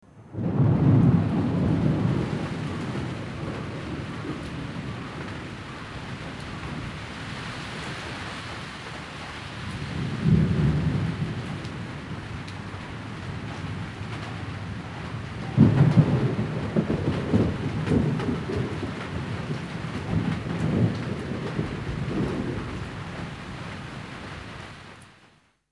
和大家分享一下暴雨素材
暴雨1.wav